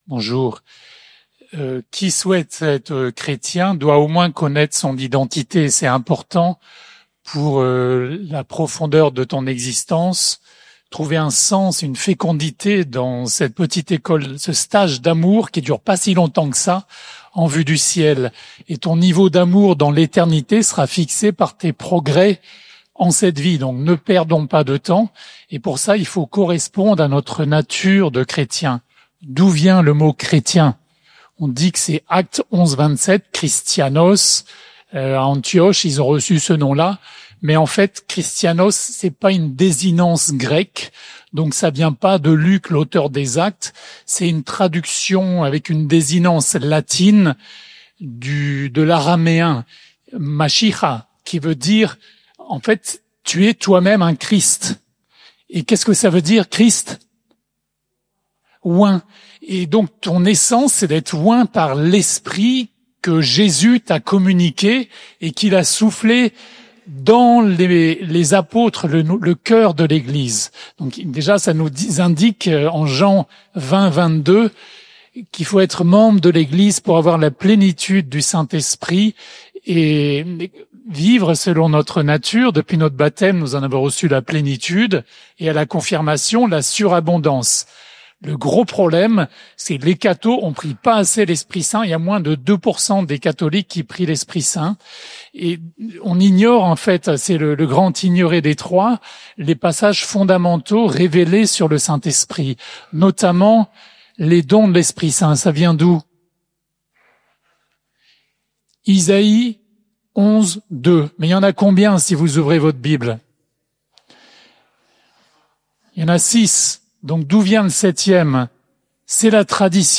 Nouan-le-Fuzelier, Festival des familles